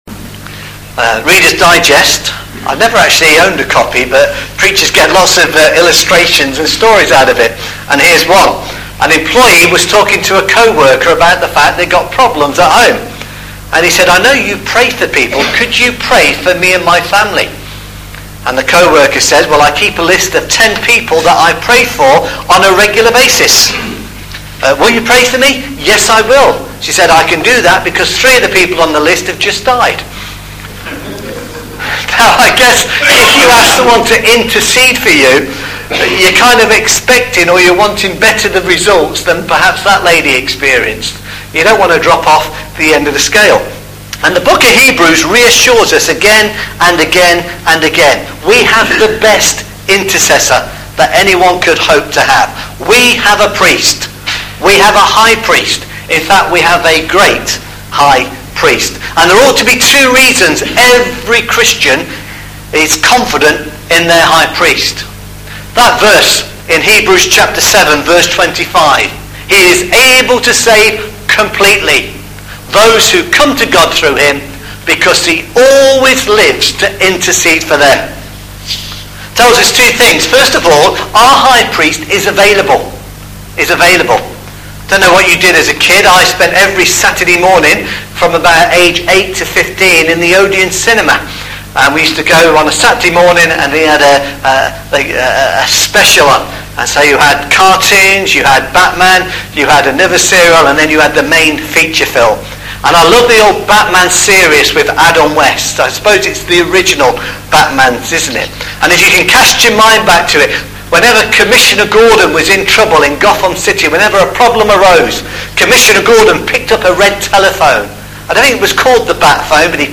The Transfiguration – Mark chapter 9 verses 2-13 – sermon